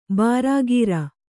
♪ bārāgīra